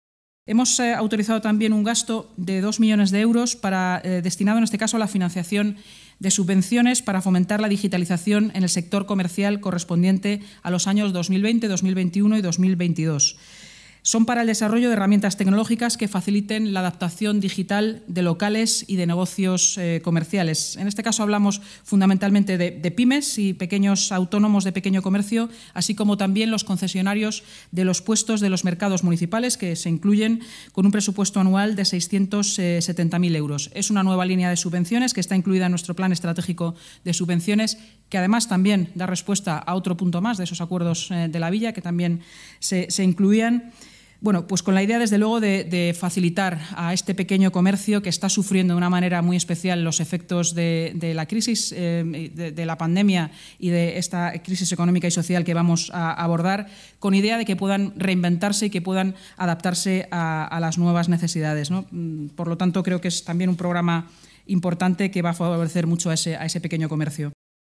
Nueva ventana:Inmaculada Sanz, portavoz Gobierno municipal y delegada de Seguridad y Emergencias